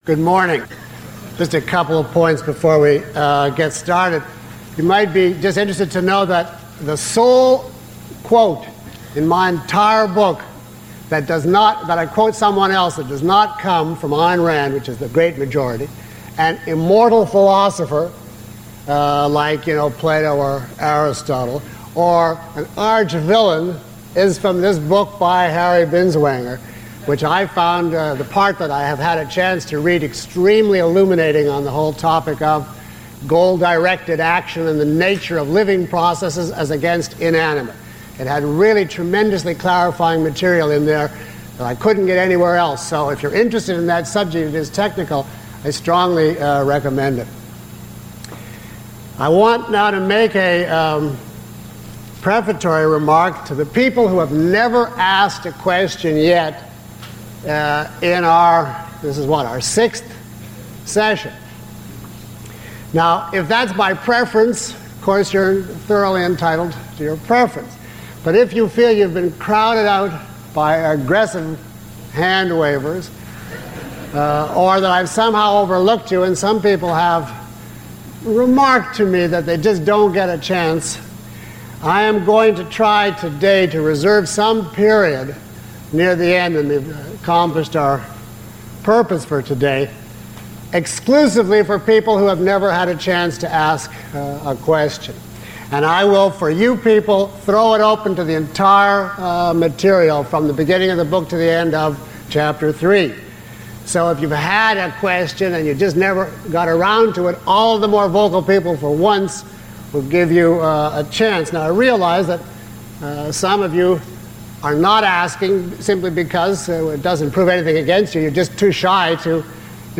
Lecture (MP3) Full Course (ZIP) Lecture Five Course Home Lecture Seven Questions about this audio?
Below is a list of questions from the audience taken from this lecture, along with (approximate) time stamps.